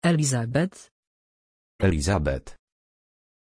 Pronunciation of Elizabeth
pronunciation-elizabeth-pl.mp3